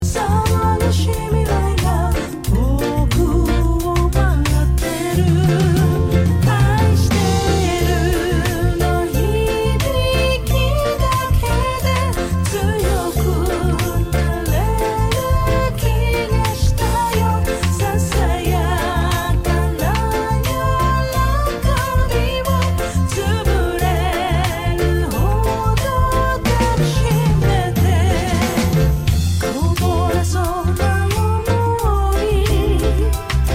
Tag       Japan R&B